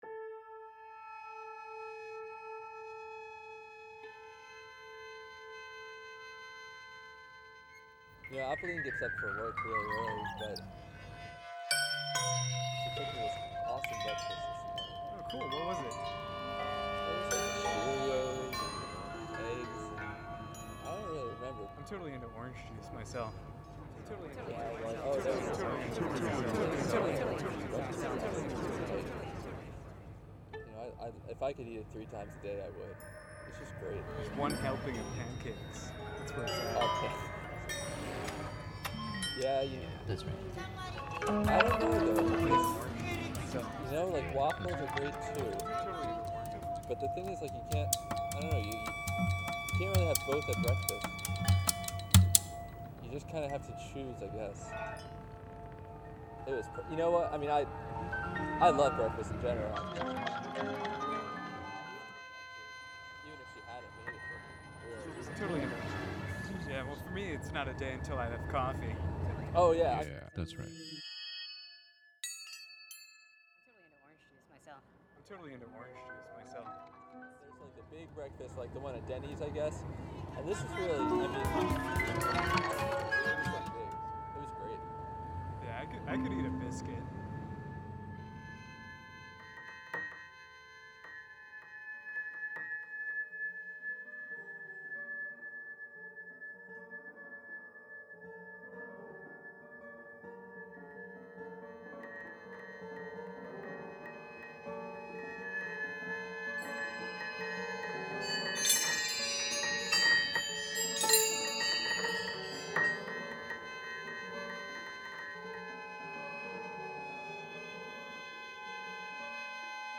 fixed media sound
For this piece, I (pseudo-)randomly selected material from the wide range of sources available to me at the time of composition (sample effects libraries, acoustic instrument samples, conversations, art music, pop music, snippets of other pieces of mine, etc.).
This became an engaging way to work: mixing each sample into an increasingly denser landscape and then continuing to add to that landscape until the emergent form was sufficiently articulated by otherwise unrelated material.